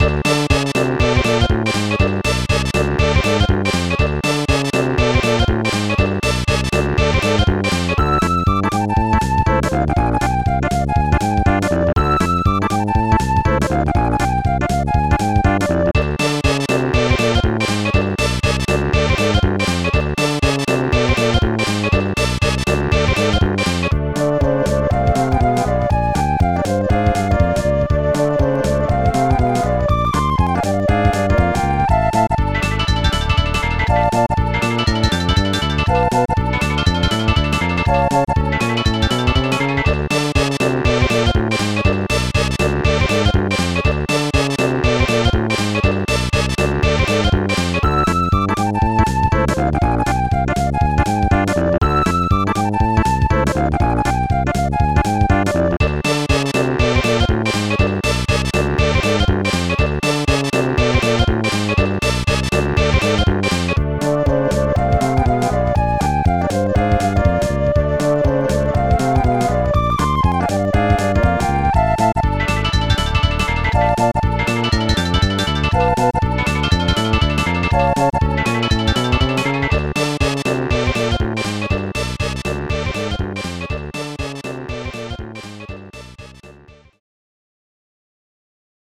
The music is catchy and very Japanese.